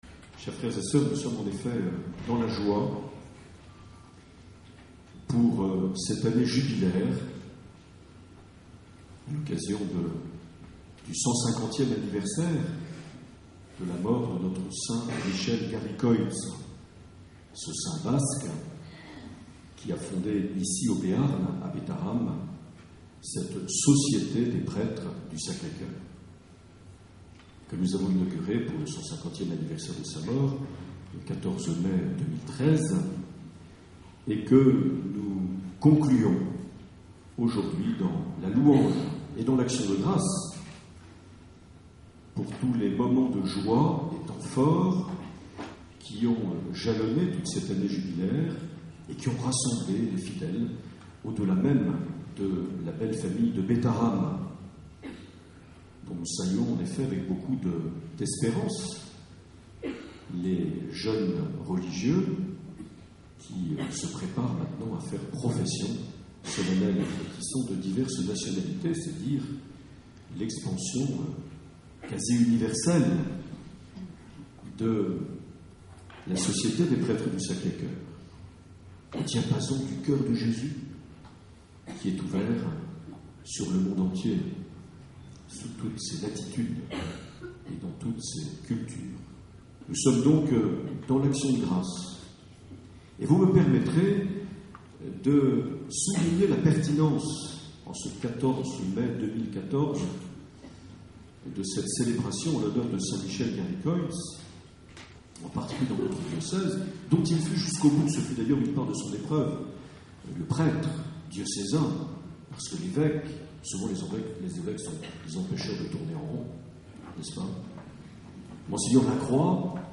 Accueil \ Emissions \ Vie de l’Eglise \ Evêque \ Les Homélies \ 14 mai 2014 - Notre Dame de Bétharram - Clôture de l’année jubilaire saint (...)
Une émission présentée par Monseigneur Marc Aillet